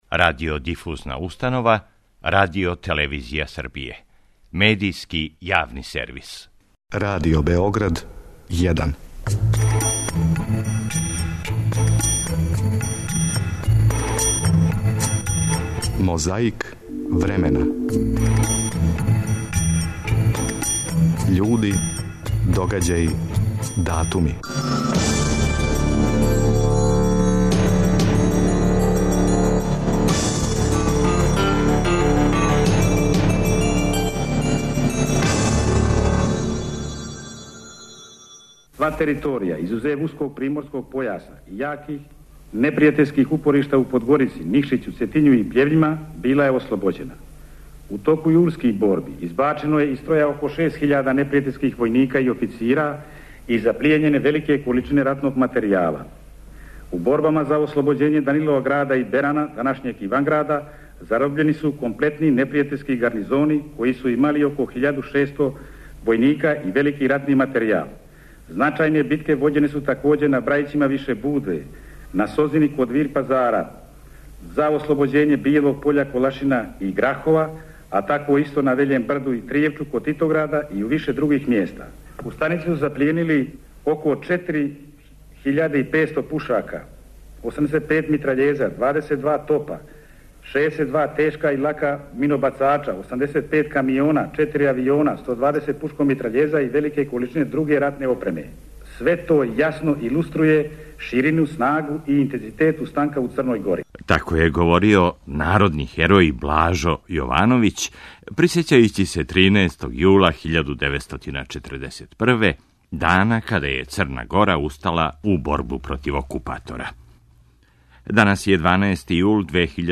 Како је говорио народни херој Блажо Јовановић присећајући се 13. јула 1941, дана када је Црна Гора устала у борбу против окупатора, чућете на почетку овонедељне борбе против пилећег памћења.